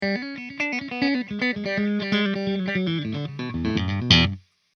Tele riff